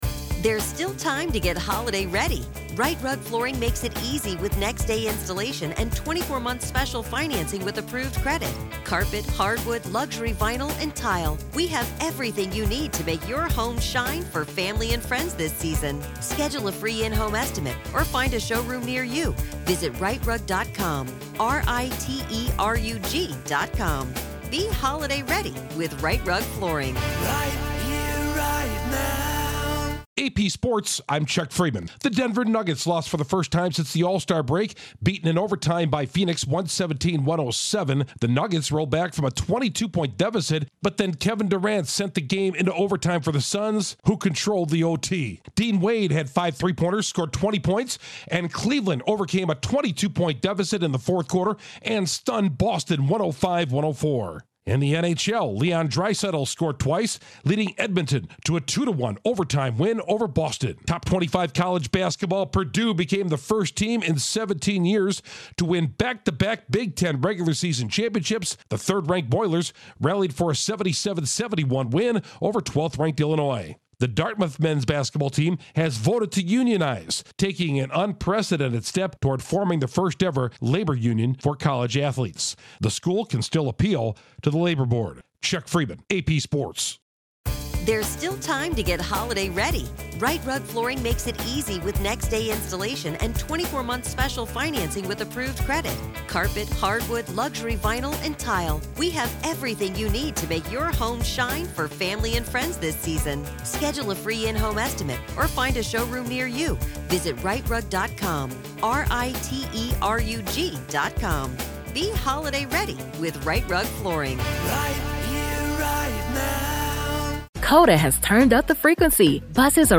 The Nuggets finally lose, the Cavs shock the NBA's top team, the Oilers nip the Bruins, Purdue takes a conference crown and a college basketball team elects to unionize. Correspondent